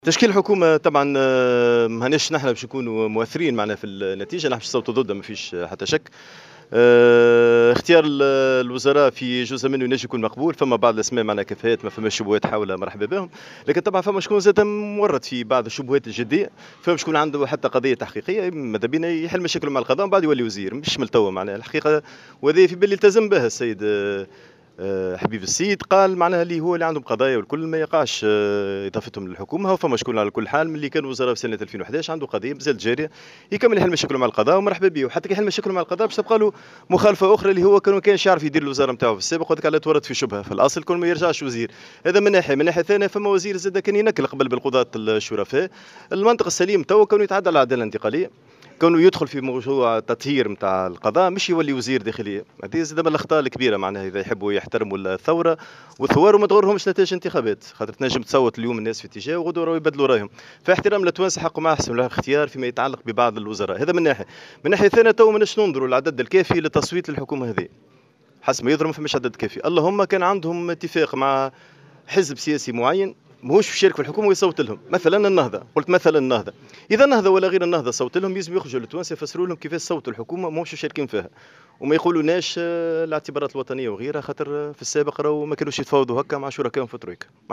Le secrétaire général du courant démocratique, Mohamed Abbou, a affirmé dans une déclaration accordée à Jawhara FM, que le gouvernement Essid comporte certaines compétences mais également des noms de personnes incompétentes.